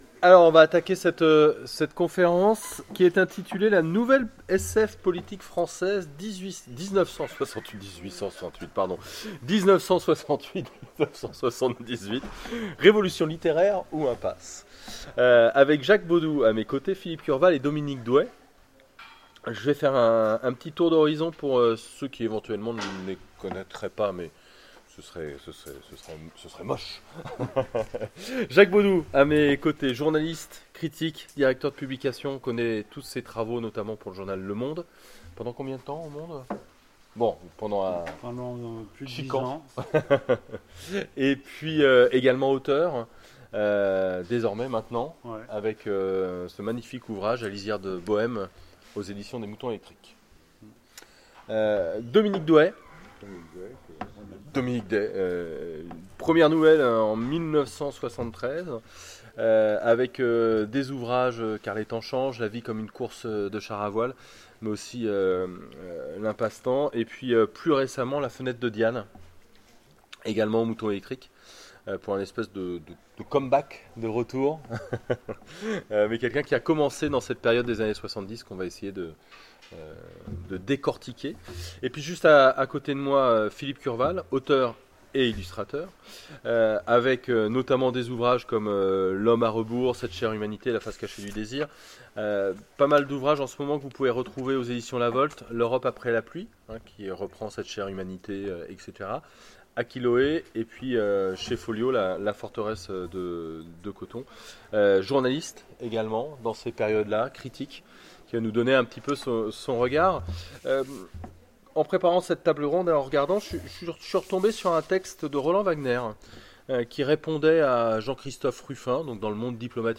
Imaginales 2016 : Conférence La nouvelle SF politique française (1968-1978)…